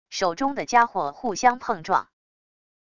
手中的家伙互相碰撞wav音频